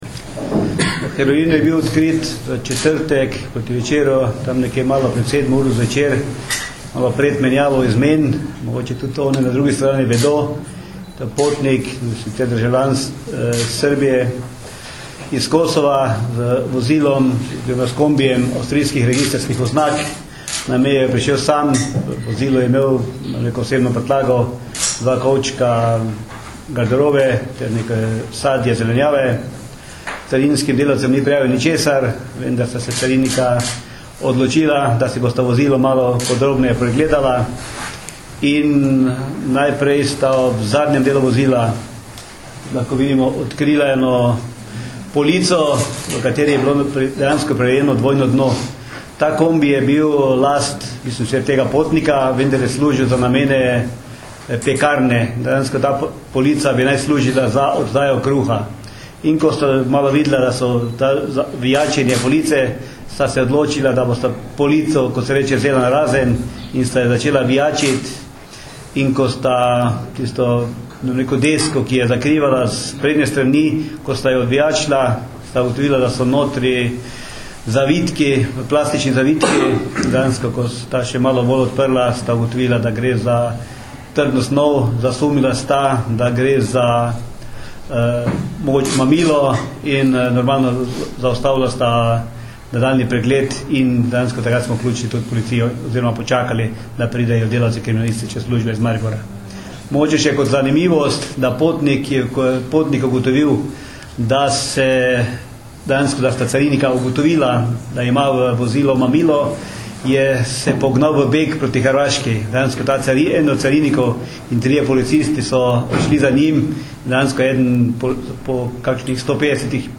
Predstavniki slovenske policije in carine so na novinarski konferenci v Mariboru danes, 3. novembra 2009, podrobneje predstavili enega večjih zasegov prepovedane droge v zadnjem času.
Zvočni posnetek izjave